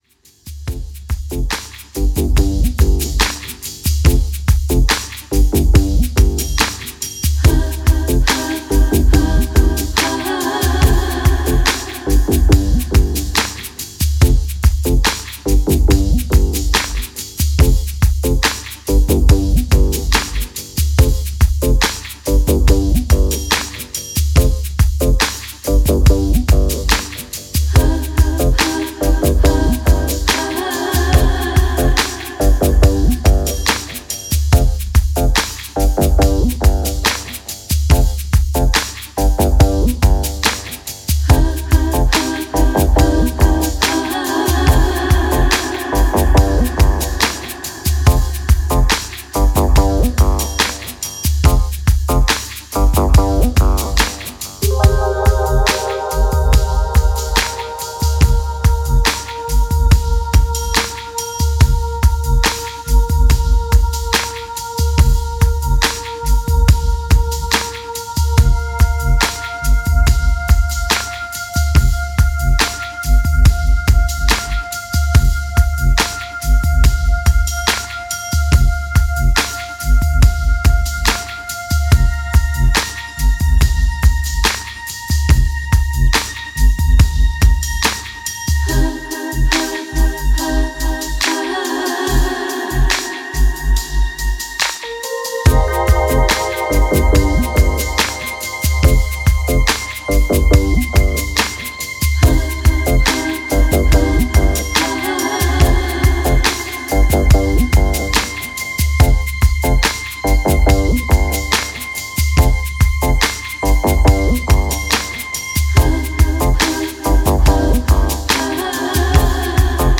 ダビーでアシッドなリミックスを収録
ジャンル(スタイル) R&B / SOUL